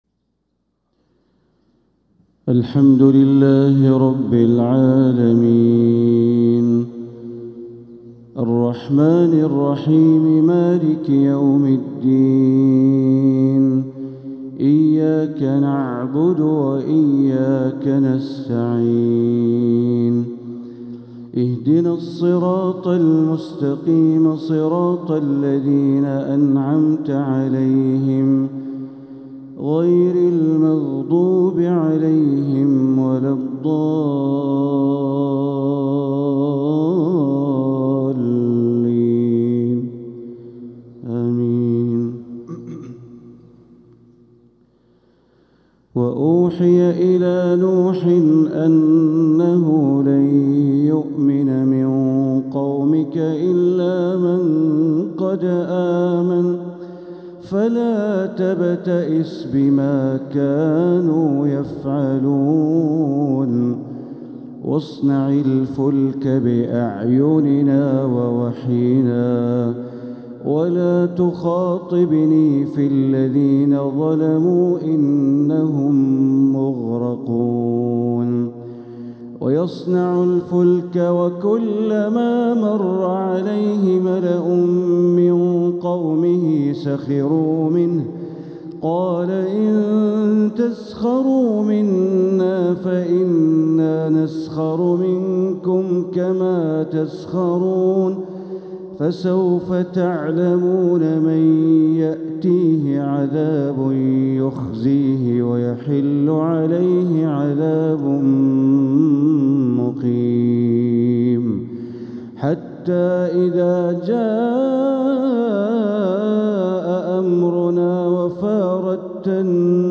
تلاوة في غاية الجمال من سورة هود | فجر السبت 2-4-1446هـ > 1446هـ > الفروض - تلاوات بندر بليلة